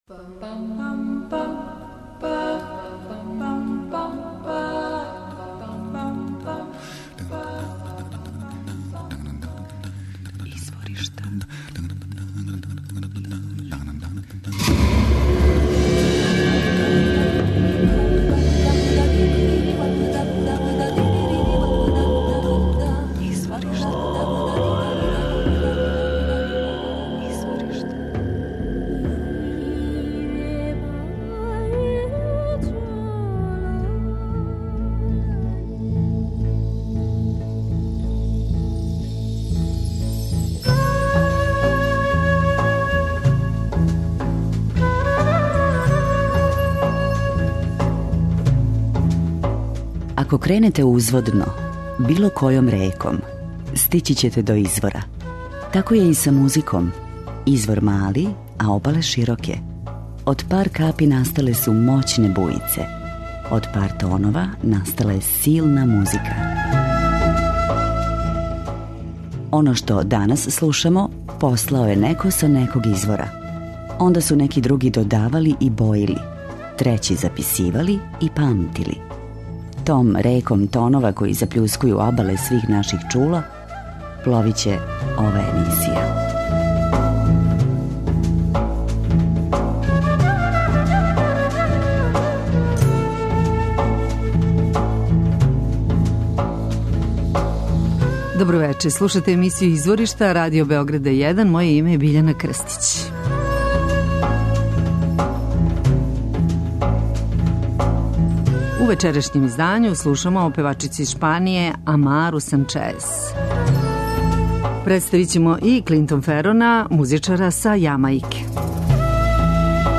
Песме са албума су личне приче из живота упаковане у живахни и пријатни глобални амбијент (музика Шпаније са доминантним утицајем латиноамеричких ритмова и кубанске музике).
преузми : 27.05 MB Изворишта Autor: Музичка редакција Првог програма Радио Београда Музика удаљених крајева планете, модерна извођења традиционалних мелодија и песама, културна баштина најмузикалнијих народа света, врели ритмови...